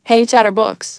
synthetic-wakewords
ovos-tts-plugin-deepponies_Billie Eilish_en.wav